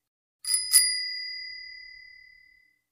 Bicycle Bell, Close Perspective.